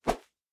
Whoosh A.wav